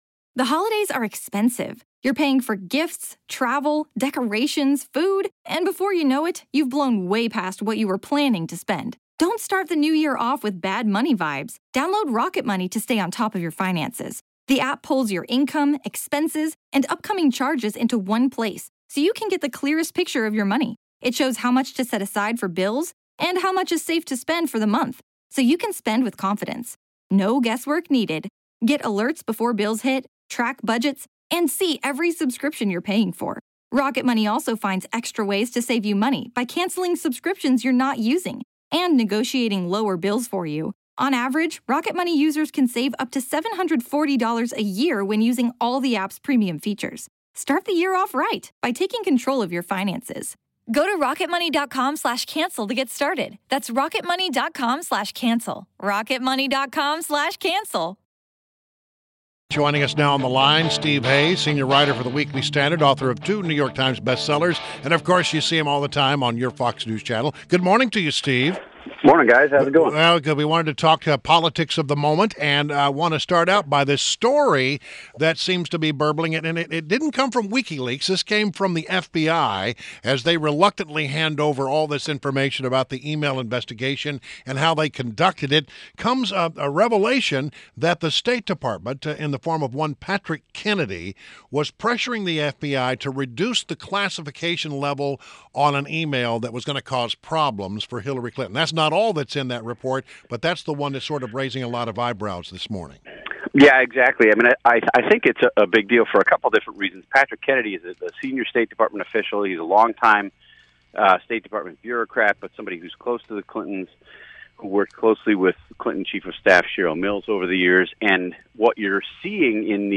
WMAL Interview - STEVE HAYES - 10.17.16
INTERVIEW — STEVE HAYES – a senior writer at The Weekly Standard, author of two New York Times bestsellers and a Fox News contributor